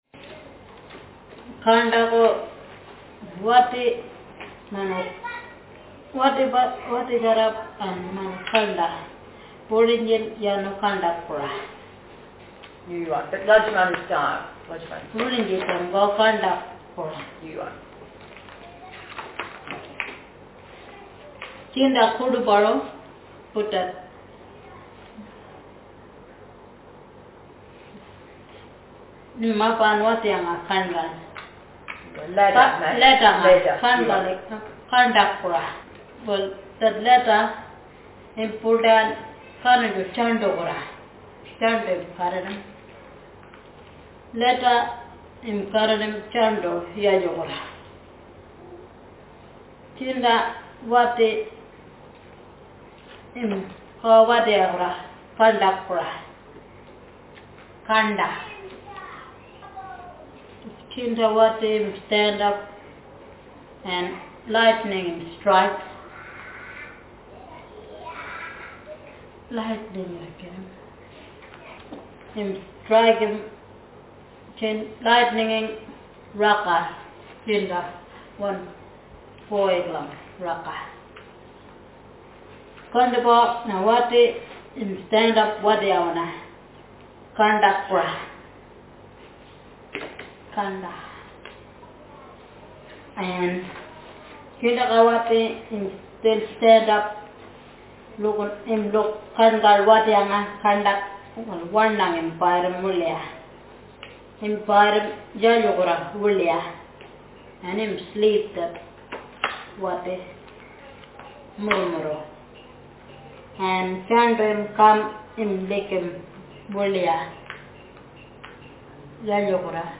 Speaker sexf
Text genrestimulus retelling